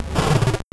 Cri de Malosse dans Pokémon Or et Argent.